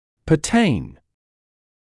[pə’teɪn][пэ’тэйн](pertain to) относиться, иметь отношение к (чему-л.)